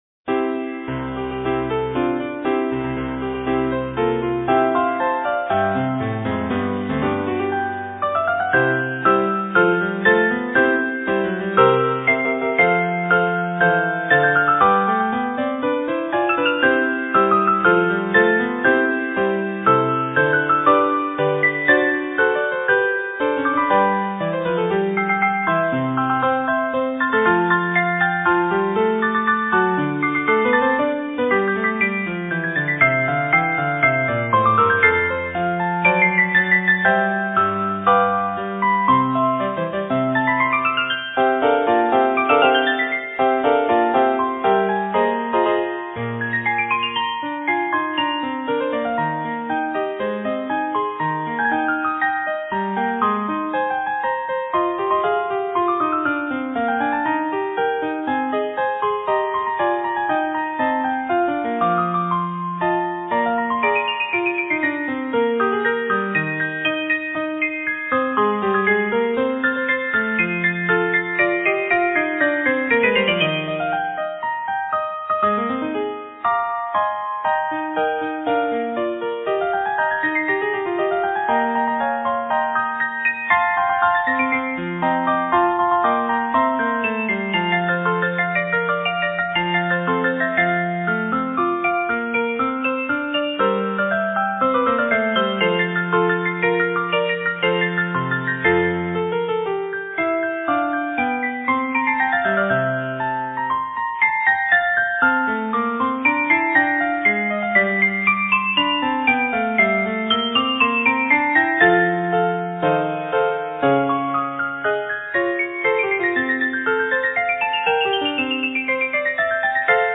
Voicing: 2 Piano 4 Hands